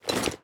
Minecraft Version Minecraft Version snapshot Latest Release | Latest Snapshot snapshot / assets / minecraft / sounds / item / armor / equip_iron2.ogg Compare With Compare With Latest Release | Latest Snapshot
equip_iron2.ogg